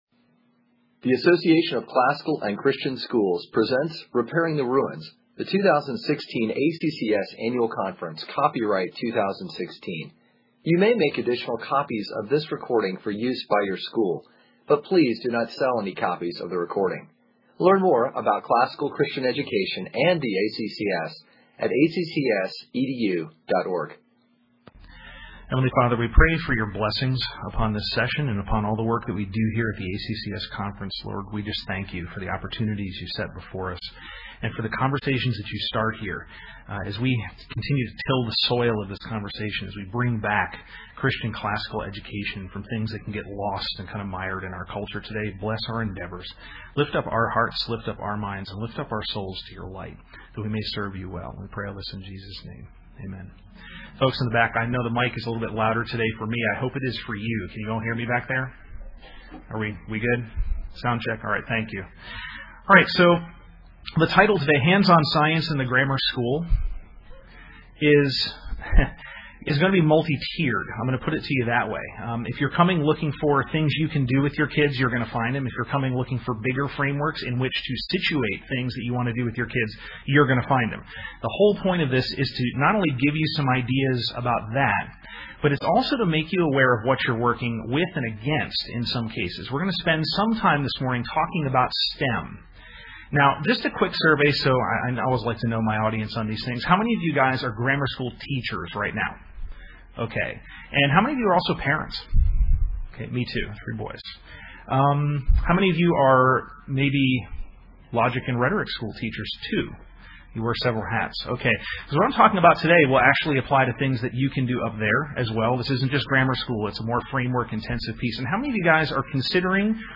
2016 Workshop Talk | 1:05:40 | K-6, Science